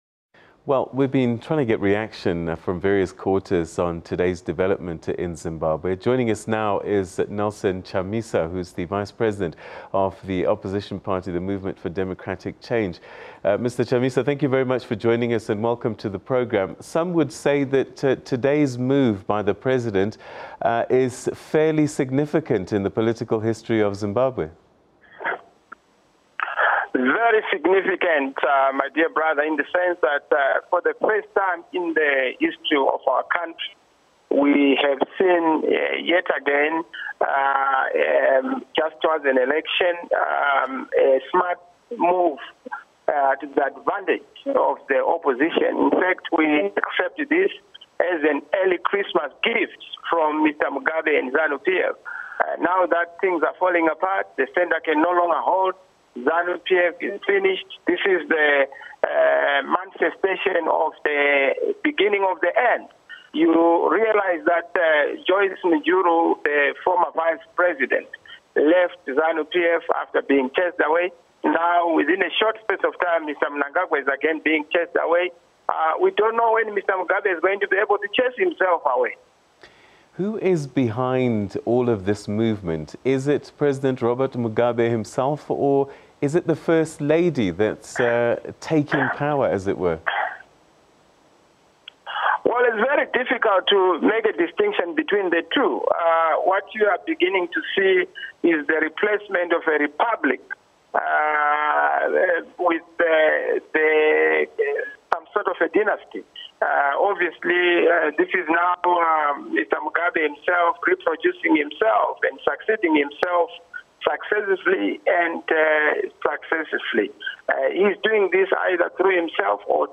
MDC-T Vice President Nelson Chamisa, in an interview with SABC News, says that President Robert Mugabe had given the opposition an early Christmas present by firing Emmerson Mnangagwa. He also added that the opposition would take full advantage of the cracks within Zanu-PF. Chamisa also said that Mugabe should also fire himself after having fired two of his vice presidents, Joice Mujuru in 2014 and Emmerson Mnangagwa on Monday.
MDCs-Nelson-Chamisa-reacts-to-axing-of-Mnangagwa.mp3